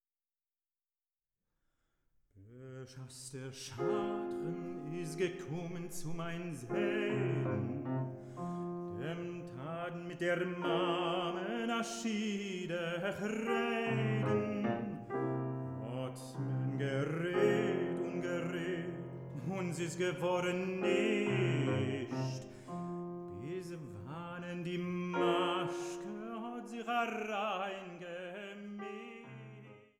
Sopran
Violine
Kontrabass
Klavier